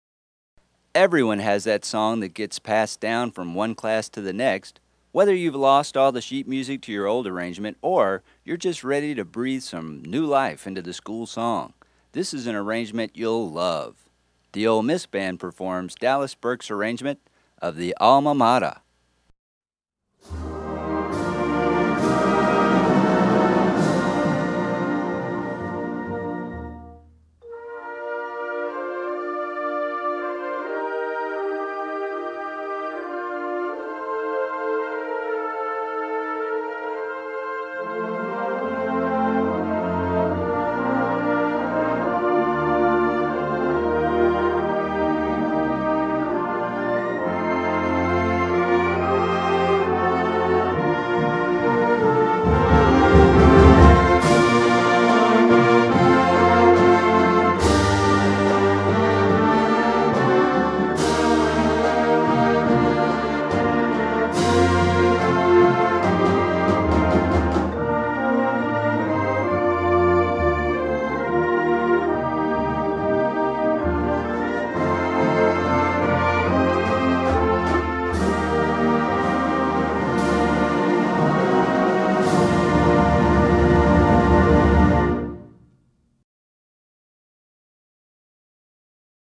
Voicing: Marching Band